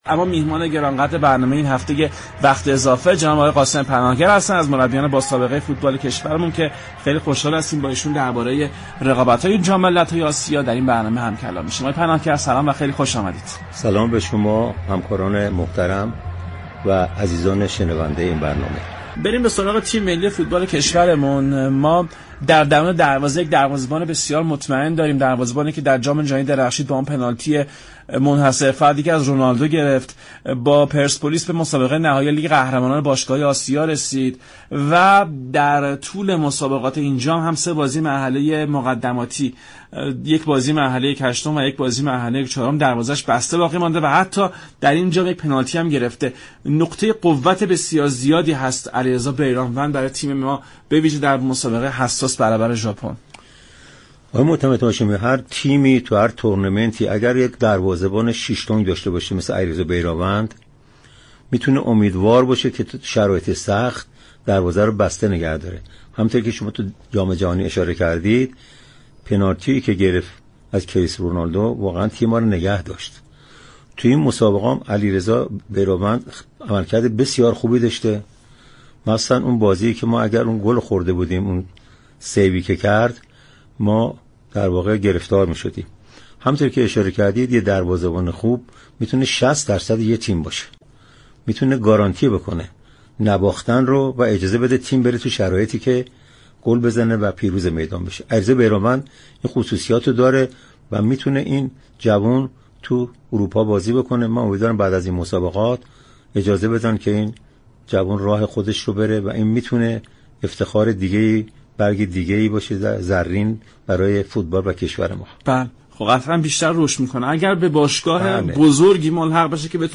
این گفت و گو را می شنوید :